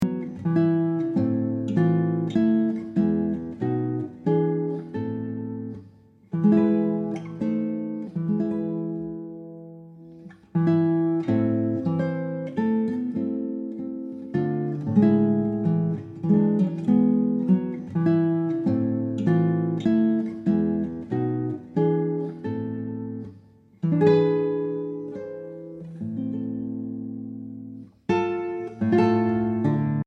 Obras para guitarra